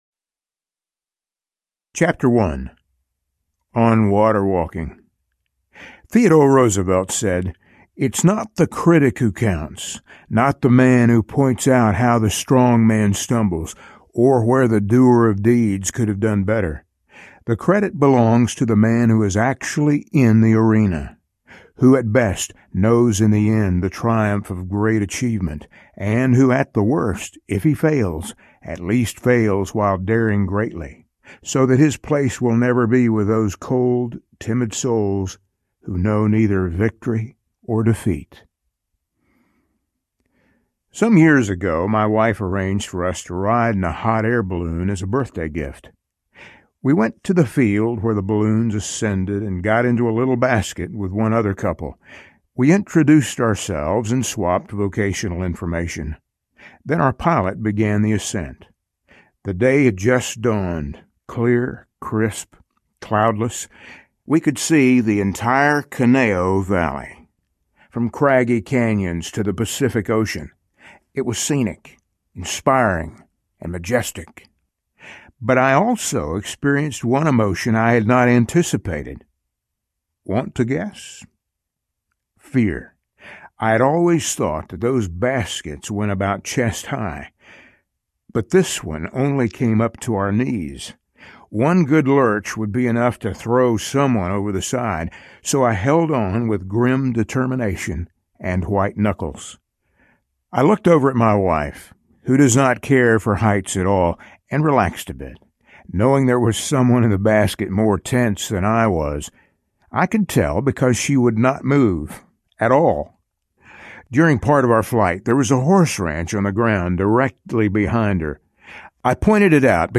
If You Want to Walk on Water, You’ve Got to Get Out of the Boat Audiobook
7.8 Hrs. – Unabridged